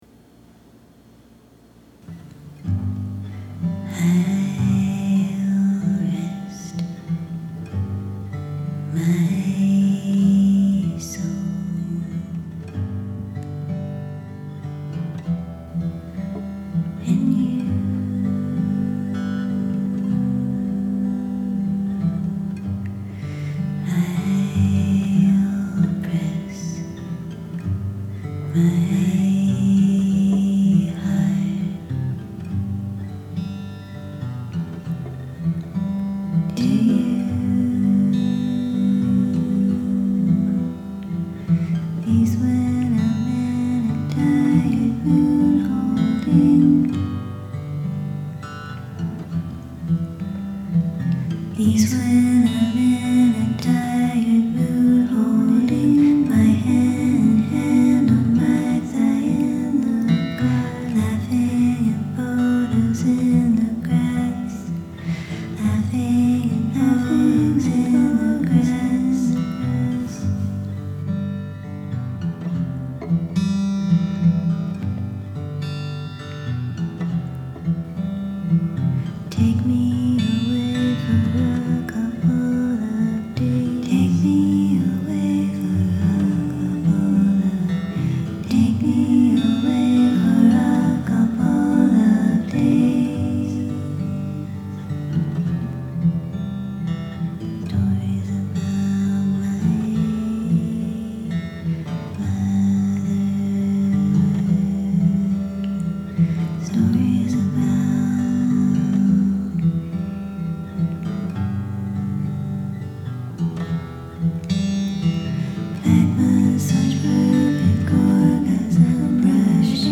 press my heart was written and shortly thereafter recorded in garageband, describing beauty inside of a relationship, april 2024